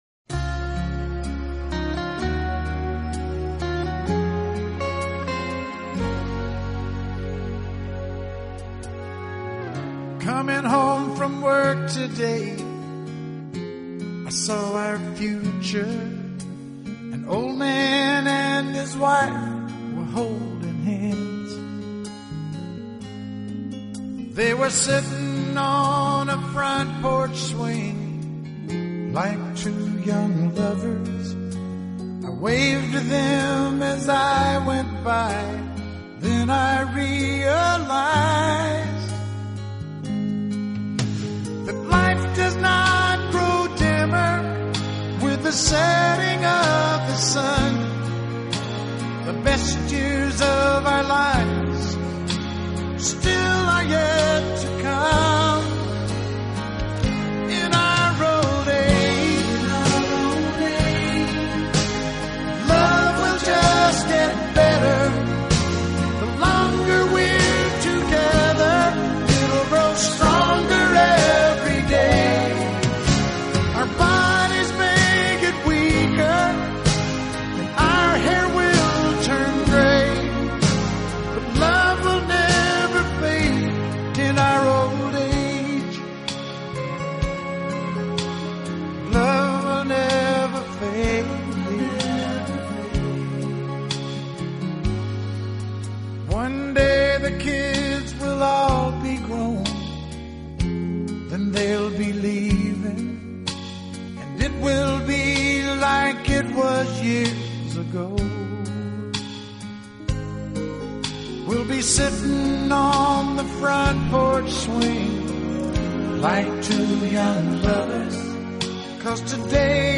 【乡村歌曲】